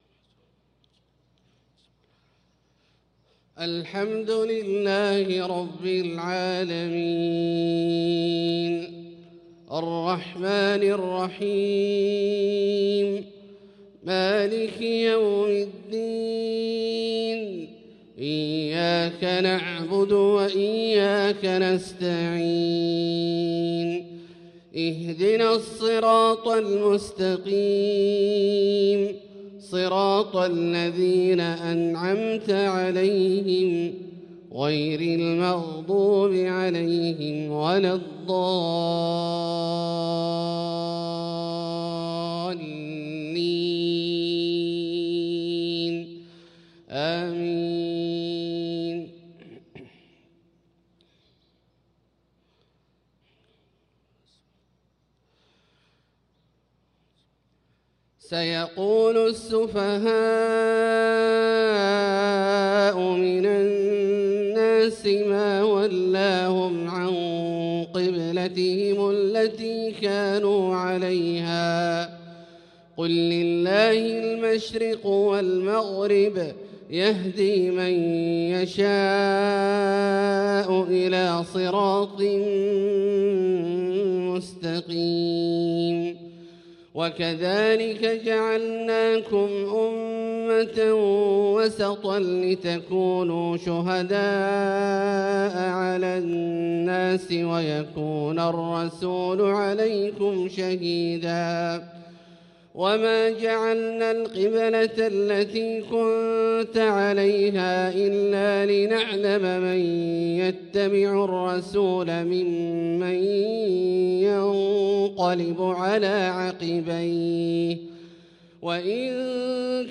صلاة الفجر للقارئ عبدالله الجهني 2 شعبان 1445 هـ
تِلَاوَات الْحَرَمَيْن .